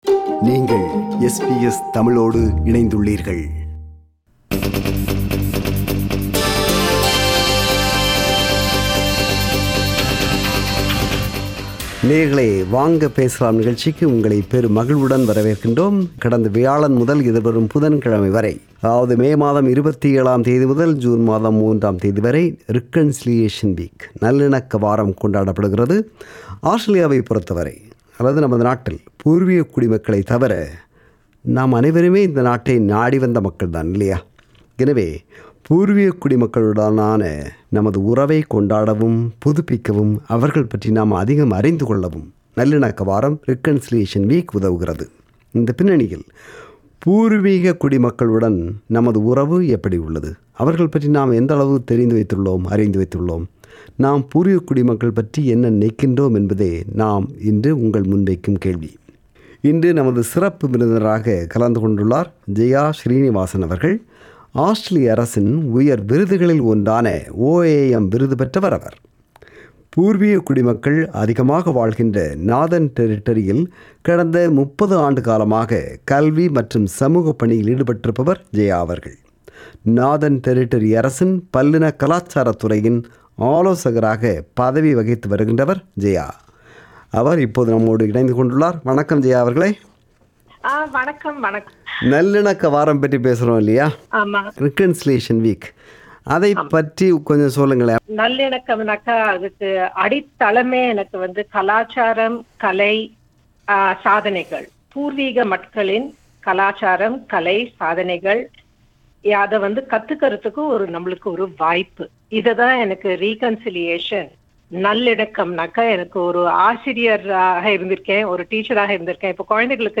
அந்த மக்களுடனான உறவை கொண்டாடவும், புதுப்பிக்கவும், அவர்கள் பற்றி நாம் அதிகம் அறிந்துகொள்ளவும் நல்லிணக்க வாரம் உதவுகிறது. இந்த பின்னணியில் பூர்வீக குடிமக்களுடன் நமது உறவு எப்படி உள்ளது? நாம் பூர்வீக குடிமக்கள் பற்றி என்ன நினைக்கின்றோம் என்ற கேள்விகளோடு ஒலித்த "வாங்க பேசலாம்" நிகழ்ச்சியில் ஒலித்த கருத்துக்களின் தொகுப்பு.